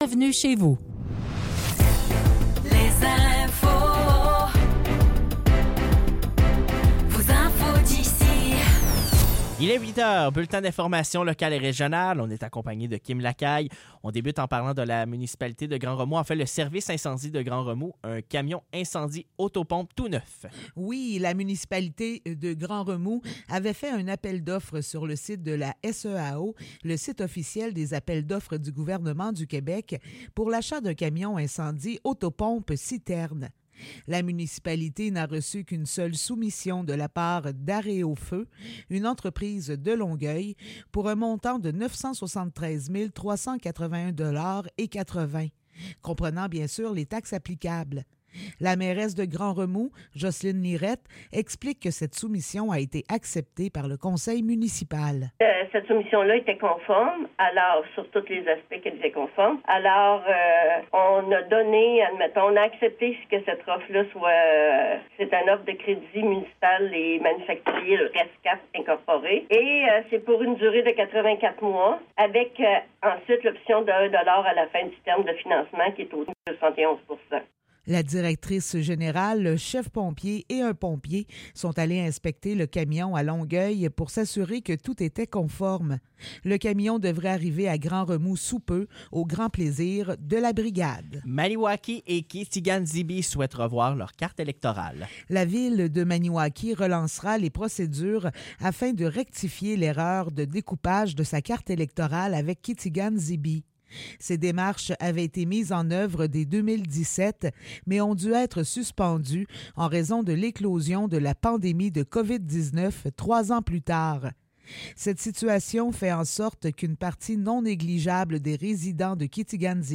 Nouvelles locales - 27 février 2024 - 8 h